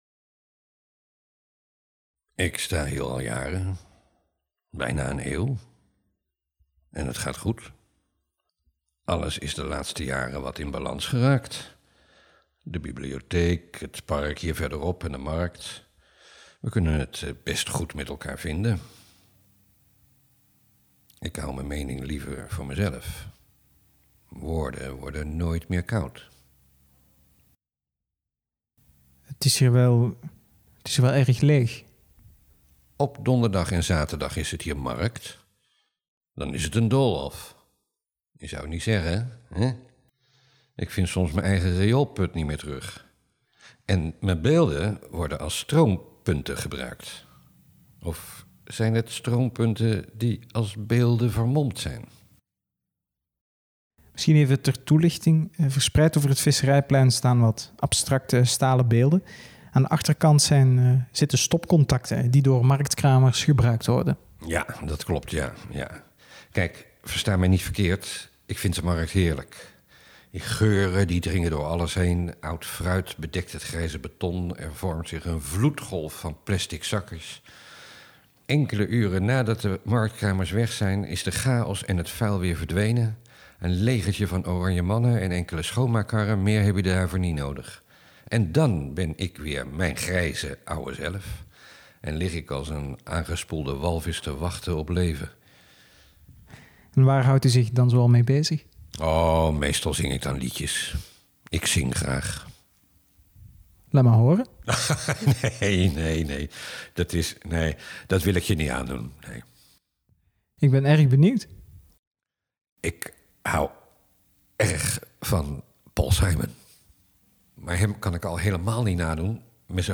Luister hier naar de Nederlandstalige versie van de podcast Stemmen van Steen – gesprekken met Bospolder Tussendijken